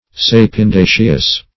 Search Result for " sapindaceous" : The Collaborative International Dictionary of English v.0.48: Sapindaceous \Sap`in*da"ceous\, a. (Bot.)